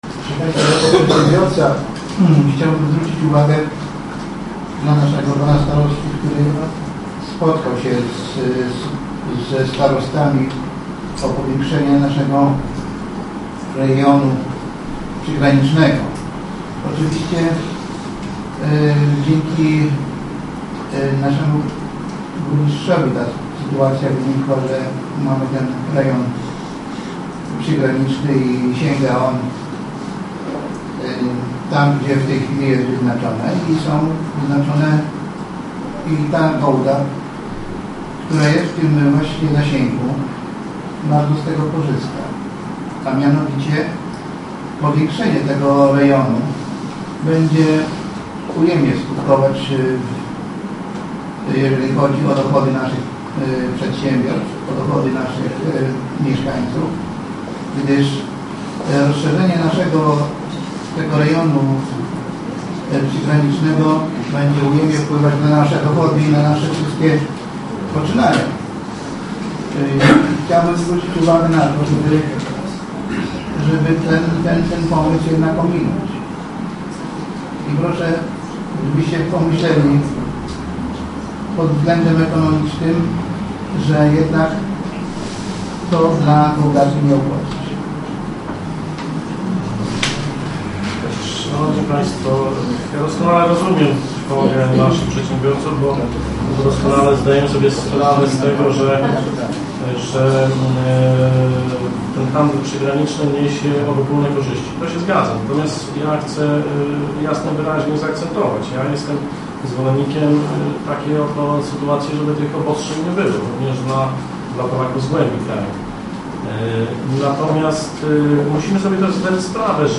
Dziś na sesji rady powiatu takiemu stanowisku Andrzeja Ciołka sprzeciwił się radny Wacław Grenda.
dyskusja: radny Wacław Grenda - starosta Andrzej Ciołek